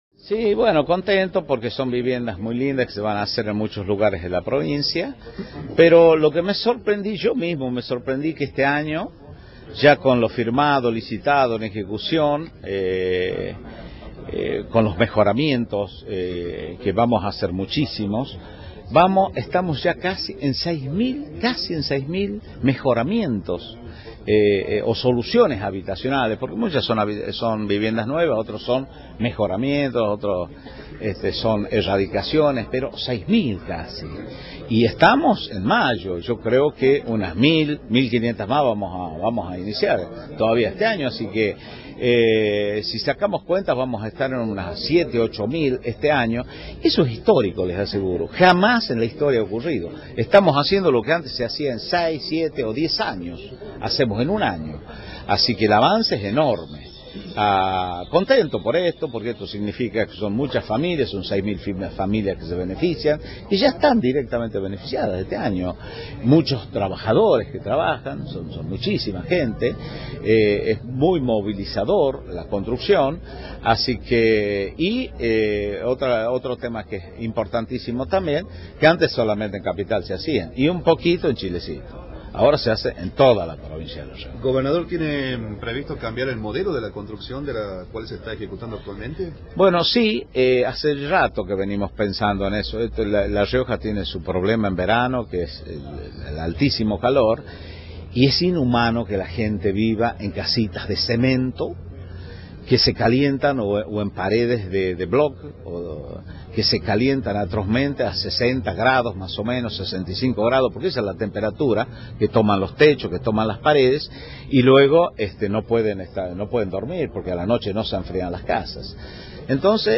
El mandatario provincial, en declaraciones formuladas en la mañana de este lunes, se refirió a los sucesos ocurridos el sábado en el marco de la asunción de las autoridades departamentales del Partido Justicialista de Famatina.